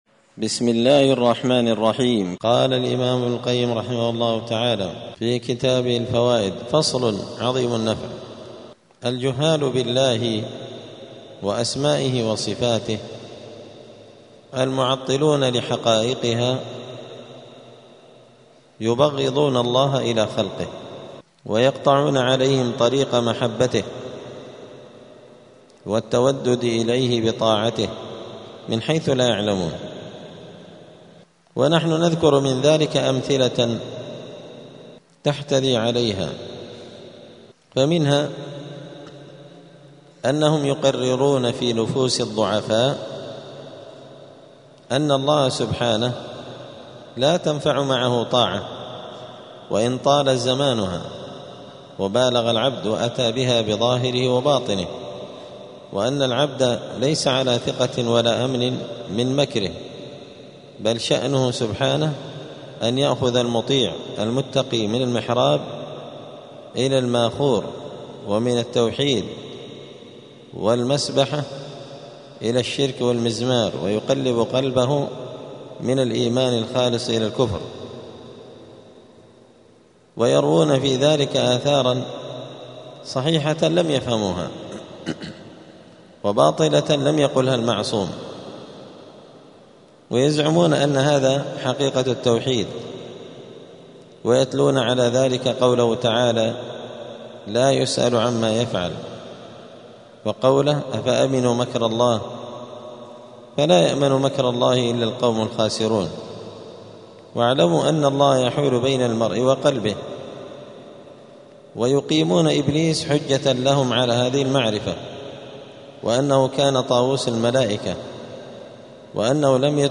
*الدرس الثاني والتسعون (92) {فصل ﻋﻈﻴﻢ اﻟﻨﻔﻊ}*